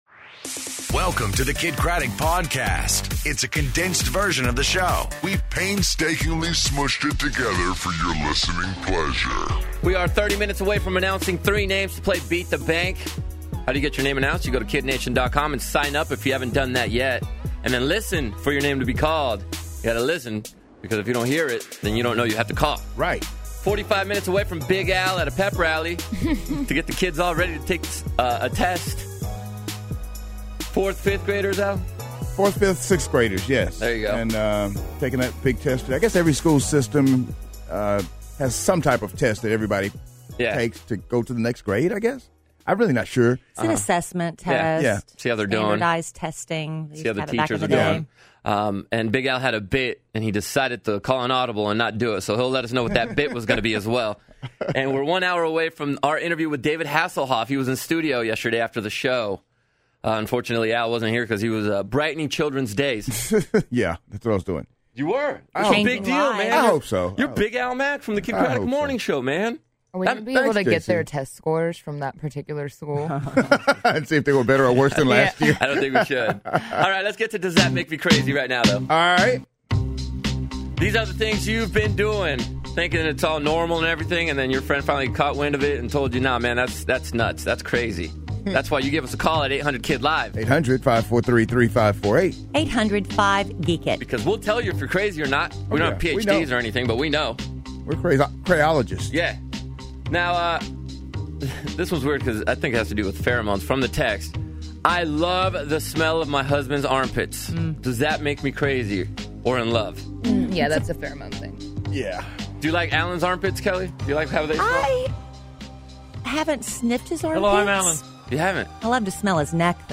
David Hasselhoff In Studio, Does that Make Me Crazy!? And Tinder Tuesday